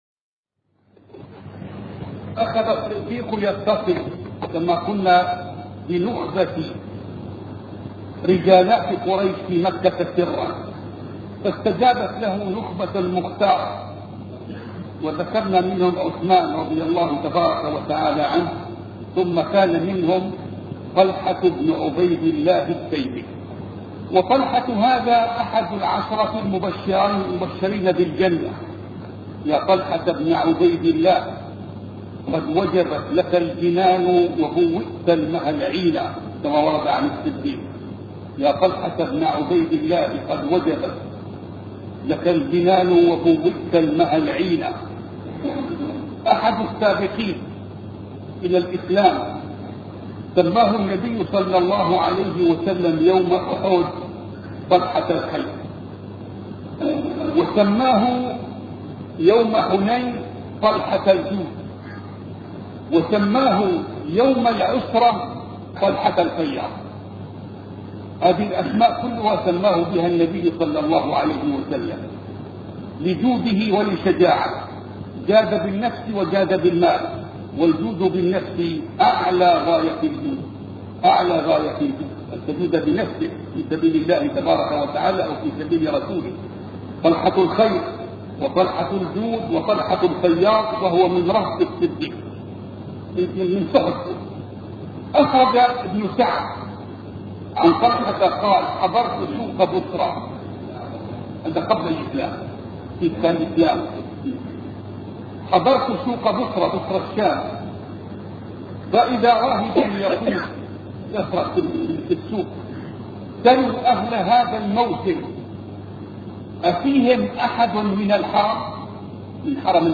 سلسلة محاطرات